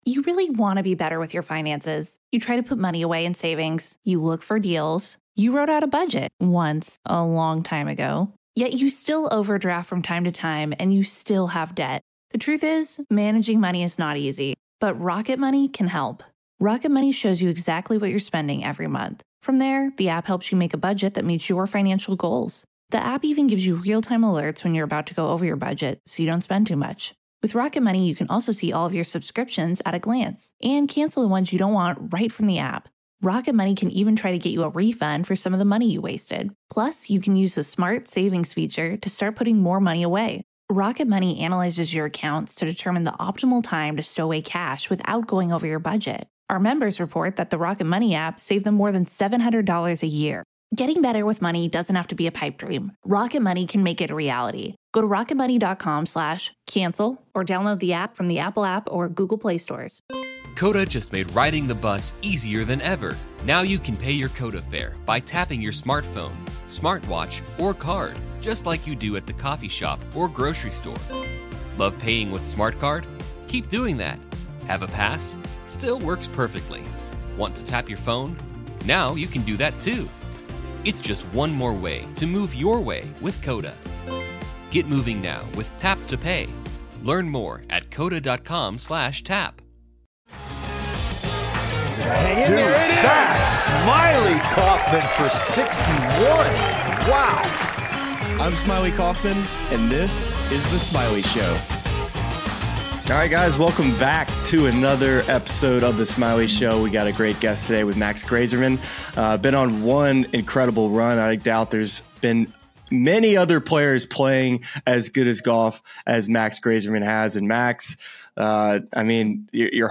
Max Greyserman Interview: The Journey To Top 50 In The World